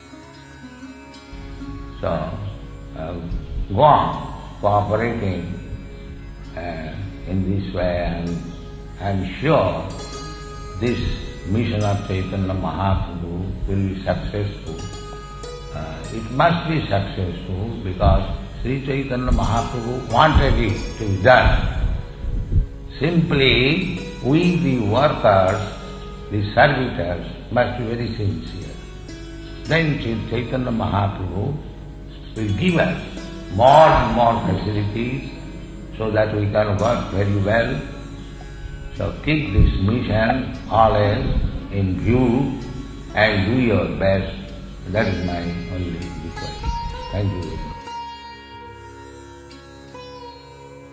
(740927 - Lecture Arrival - Mayapur)